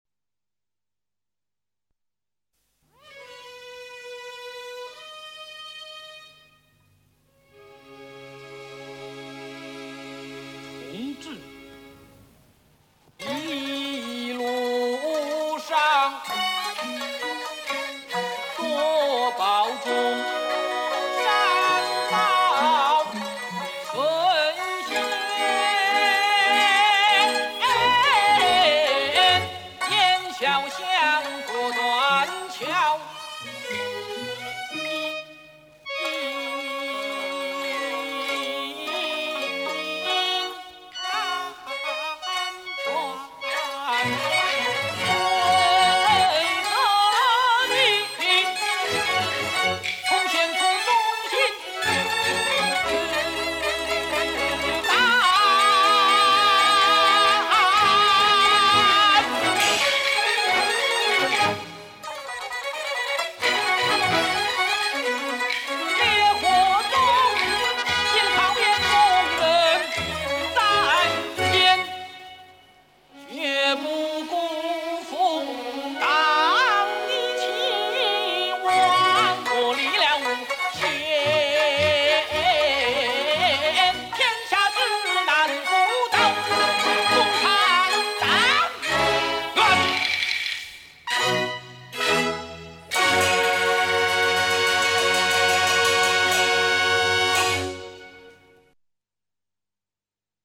【二黄快三眼】